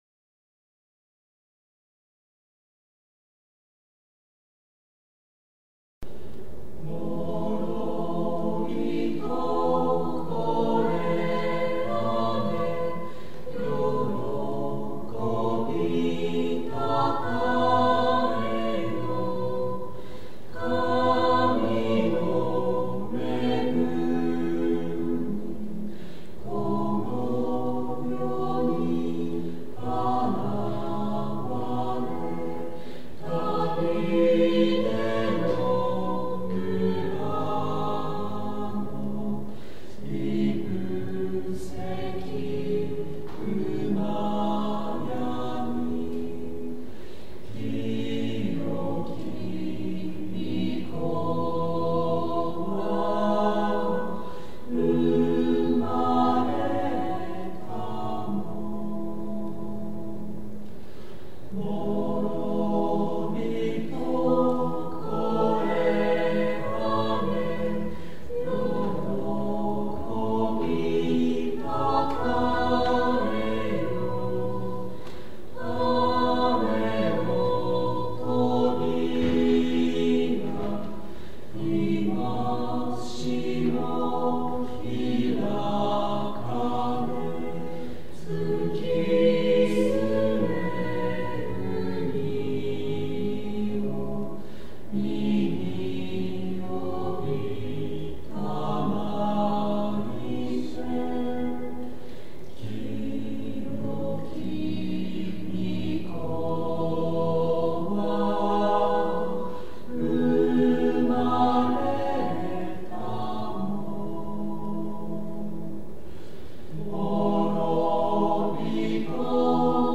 讃美歌二曲
フルセズコーラス　1997年9月28日　文翔館にて